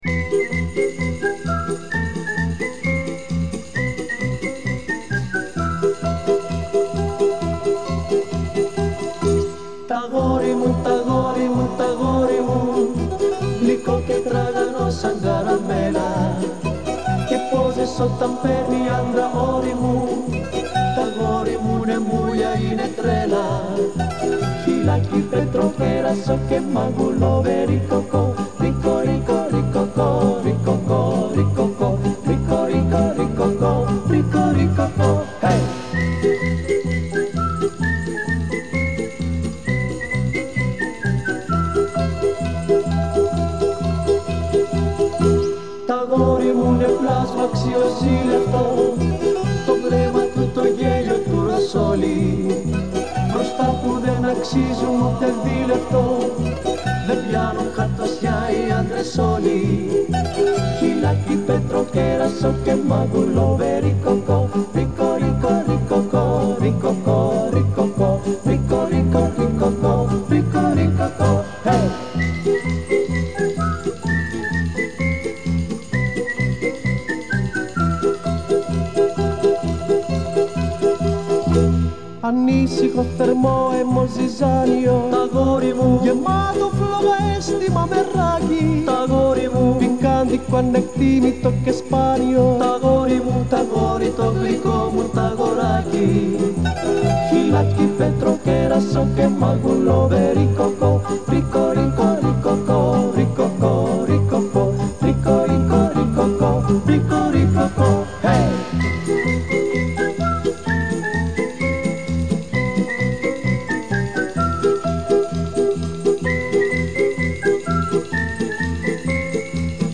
Музыкальное трио из солнечной Греции.
Музыкальный стиль Laika.
Genre: Folk, Instrumental